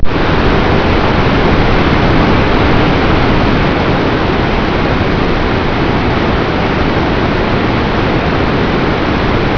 Rock formations in front of waterfalls
Circuito Superior, Iguazu Falls, Argentina